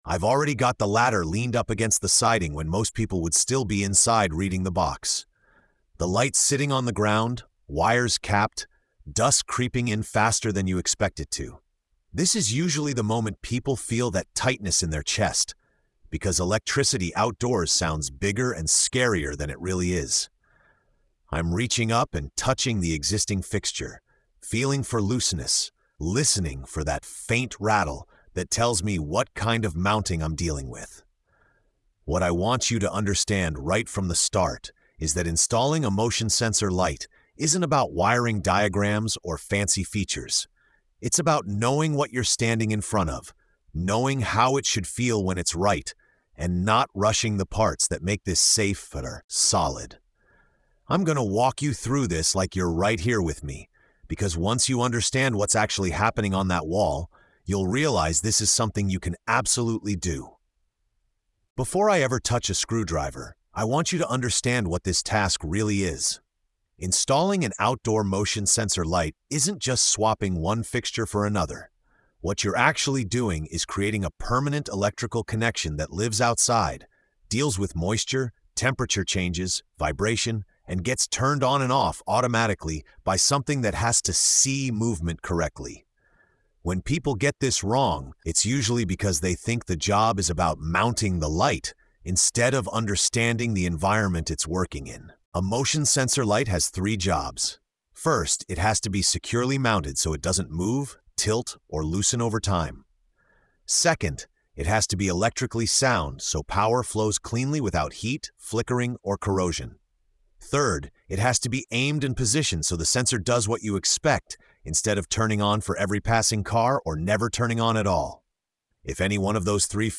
In this episode of TORQUE & TAPE, the listener is taken step by step into the real-world moment of installing an outdoor motion-sensor light, not as a technical checklist, but as a lesson in judgment, awareness, and confidence. Through calm, first-person storytelling, the episode explores how understanding electricity, environment, and human movement transforms a seemingly intimidating task into an empowering one. The tone is grounded, steady, and reassuring, reminding listeners that competence is built through patience and attention, not fear.